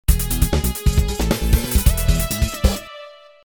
2.0 Review の「Comp]」で使ったネタに、ベースとシンセリードを重ねて試してみました。
オリジナルはシンセ音が弱めなので、テーマは “シンセリードを持ち上げる” です。